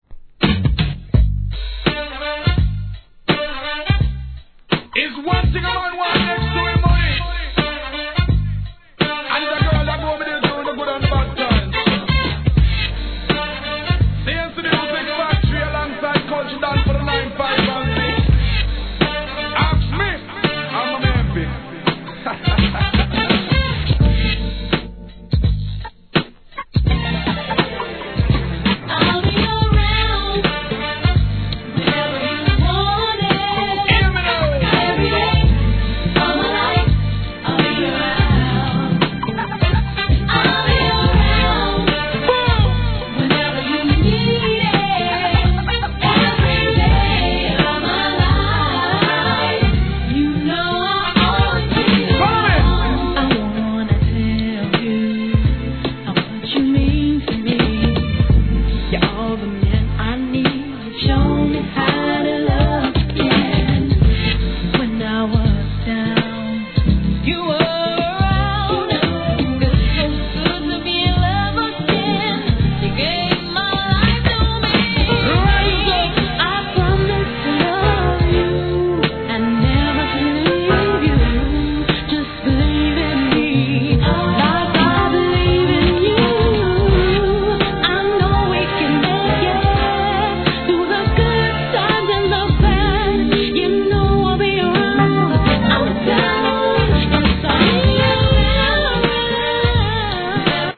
1. HIP HOP/R&B
ラガ・テイストのイントロからグッと引き付けるNICEミディアムR&B!ヴォーカルWORKも素晴らしいです!